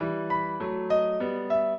piano
minuet9-3.wav